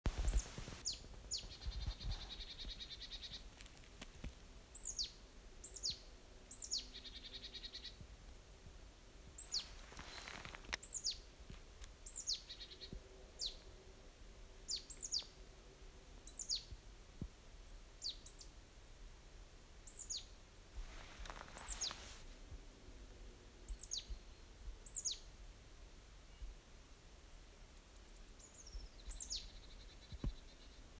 ParpalSiikalahti21.mp3